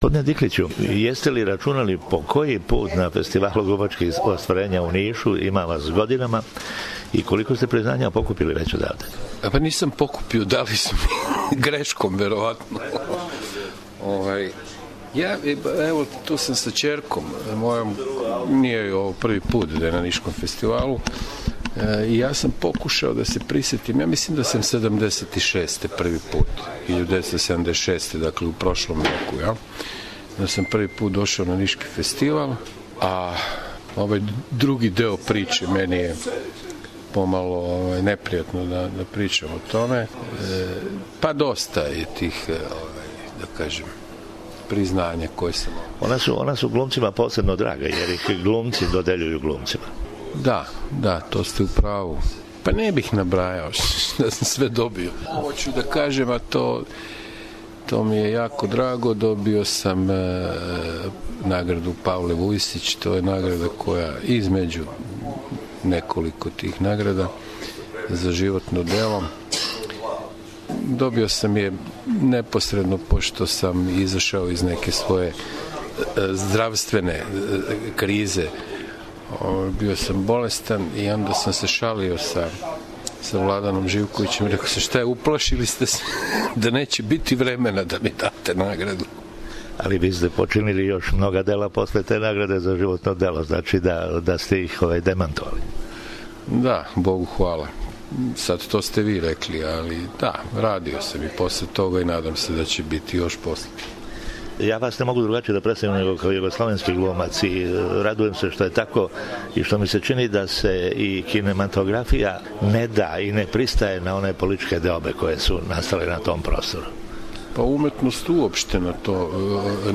Током интервјуа који је дао за наш програм Диклић каже да је глума начин живота и да јој као таквој остаје посвећен, али такође каже, када би поново могао да бира свој животни позив, вероватно не би изабрао глуму.